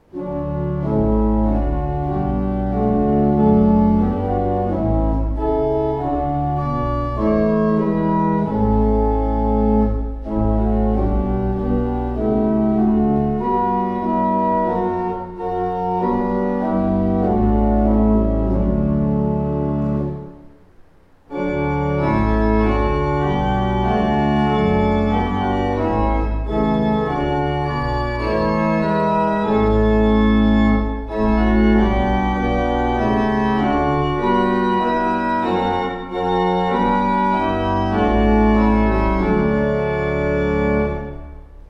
Organist Gloucestershire, UK